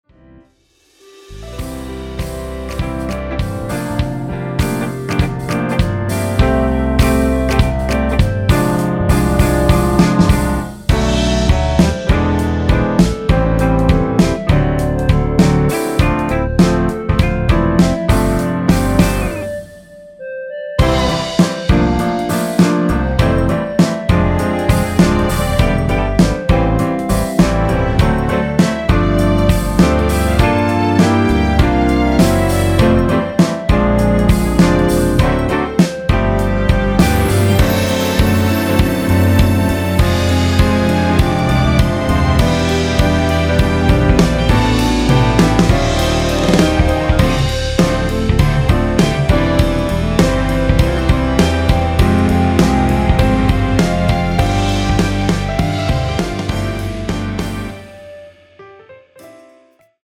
원키에서(-3)내린 (1절+후렴)으로 진행되는 멜로디 포함된 MR입니다.
◈ 곡명 옆 (-1)은 반음 내림, (+1)은 반음 올림 입니다.
앞부분30초, 뒷부분30초씩 편집해서 올려 드리고 있습니다.
중간에 음이 끈어지고 다시 나오는 이유는